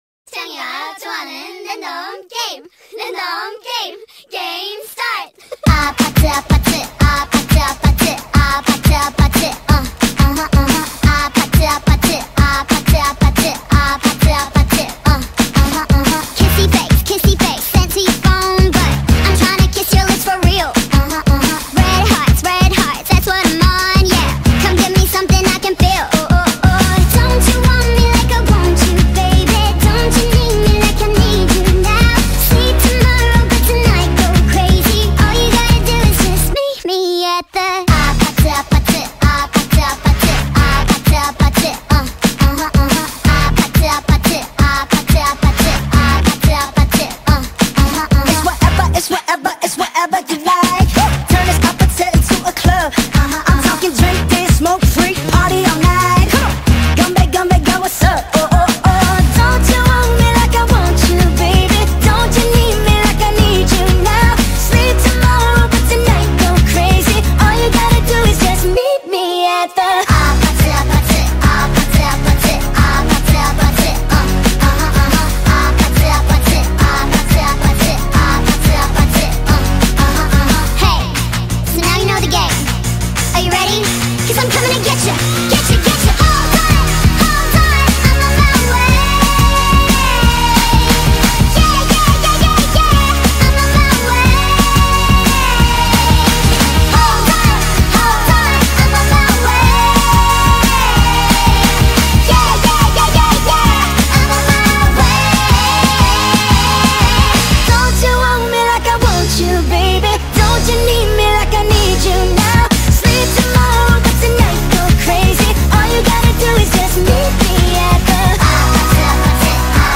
Speed Up TikTok Remix